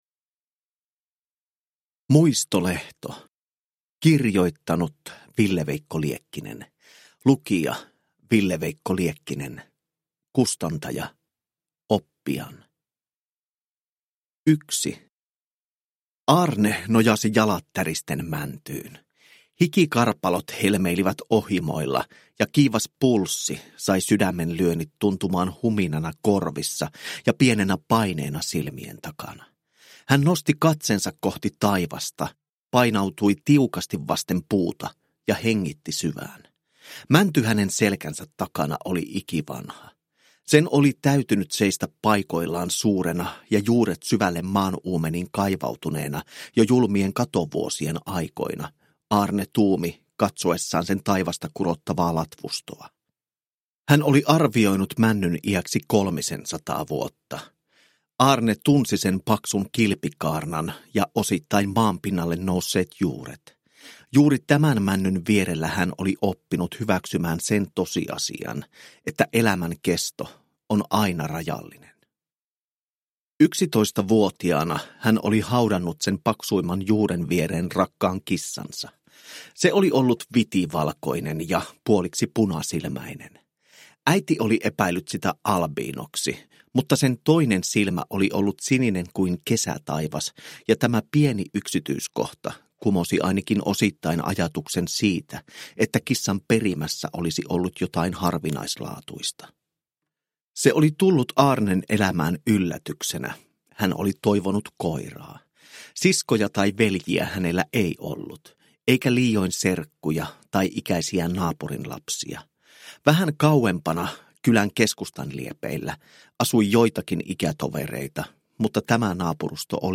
Muistolehto – Ljudbok – Laddas ner